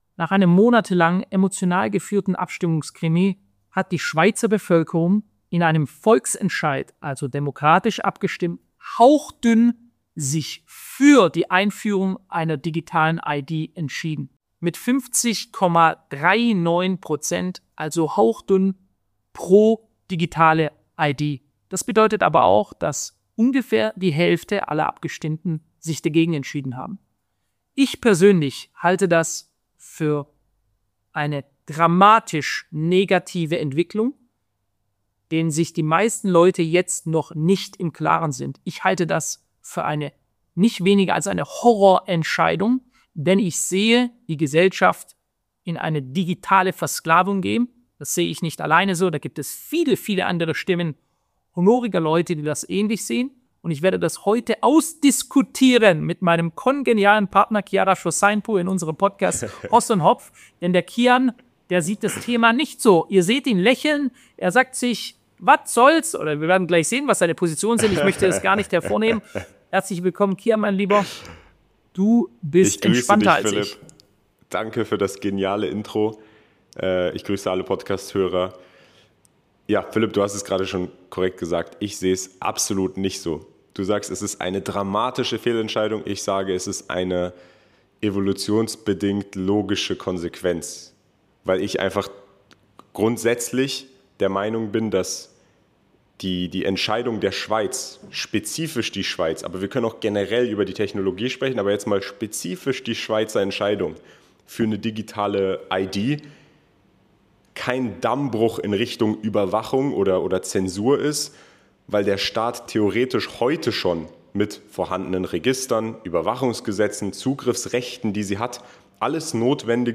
Eine facettenreiche Diskussion über Technologie, Gesellschaft, Politik und Vertrauen.